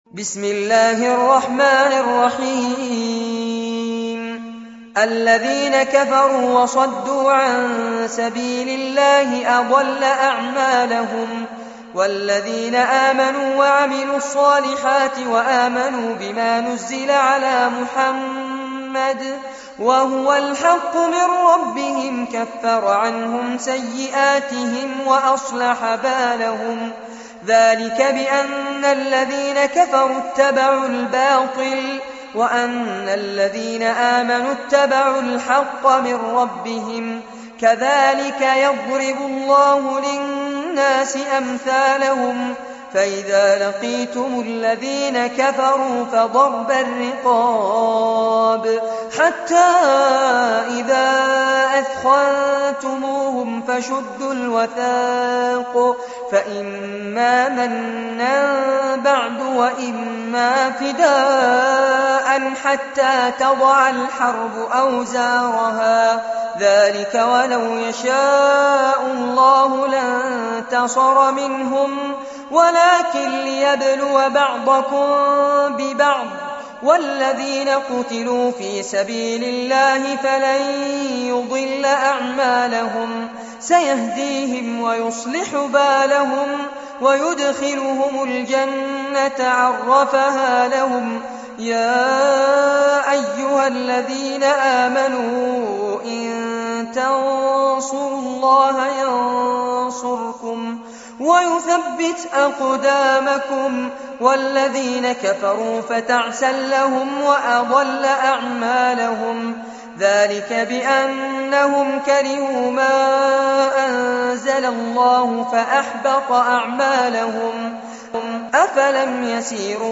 عنوان المادة سورة محمد- المصحف المرتل كاملاً لفضيلة الشيخ فارس عباد جودة عالية